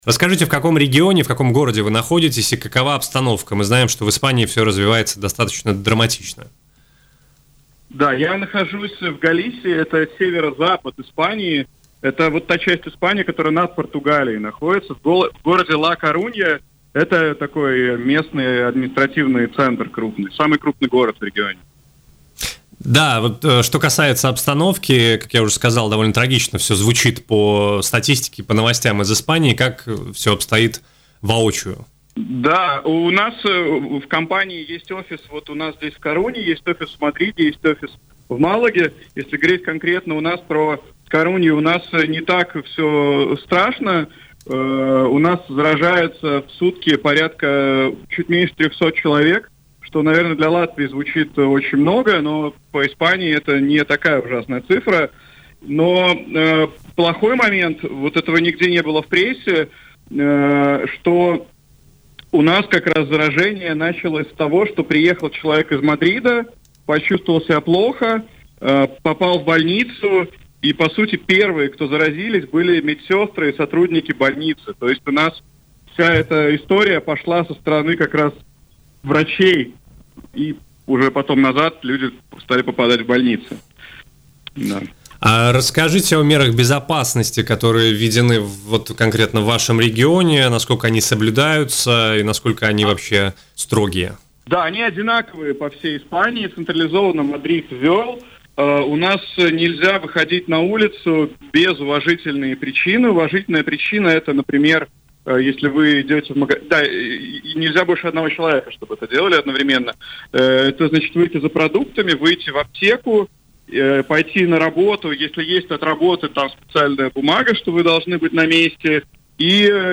Программист из Испании на Baltkom: жители предлагают собак в аренду, чтобы обойти карантин